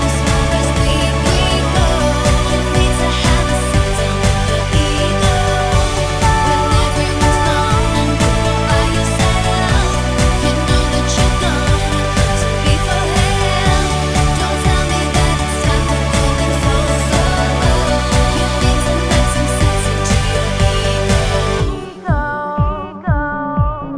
With Filtered Vox Pop (2010s) 3:02 Buy £1.50